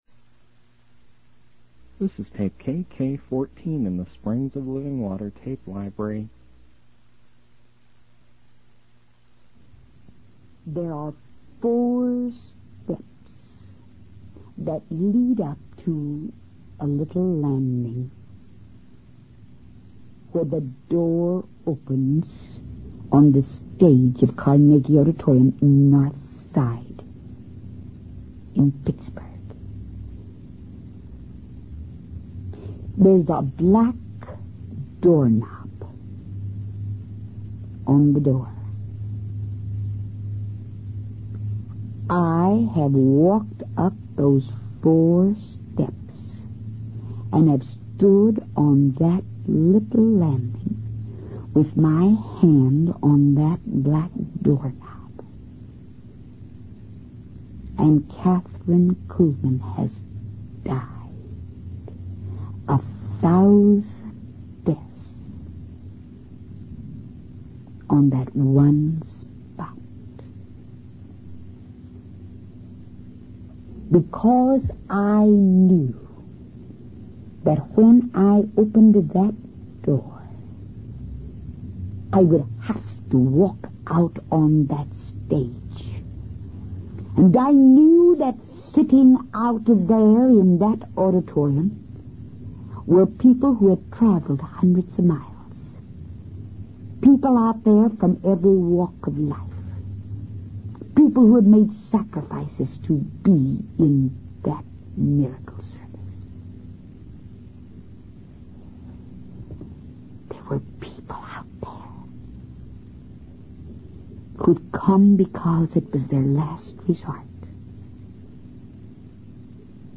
In this sermon, Catherine Coogman reflects on her role as a preacher and the responsibility that comes with it. She describes the anticipation and fear she feels before stepping onto the stage to deliver her message.